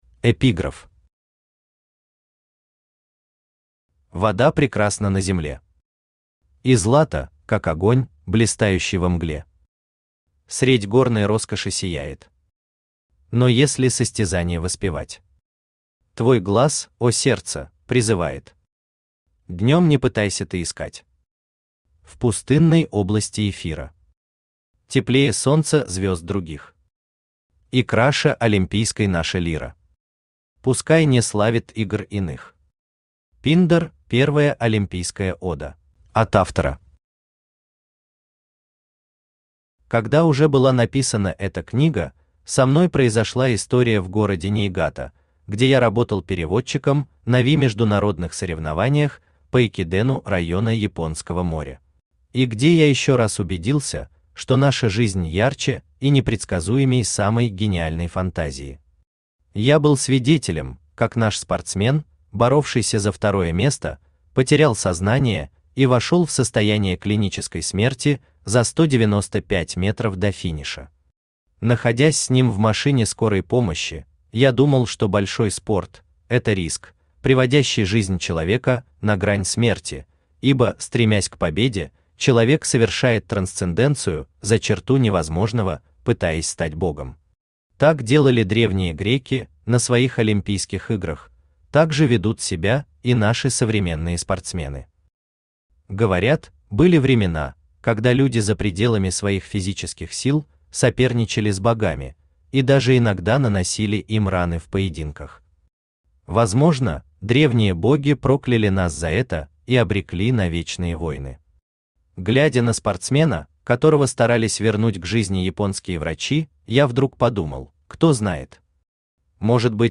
Аудиокнига Олимпийские игры | Библиотека аудиокниг
Aудиокнига Олимпийские игры Автор Владимир Фёдорович Власов Читает аудиокнигу Авточтец ЛитРес.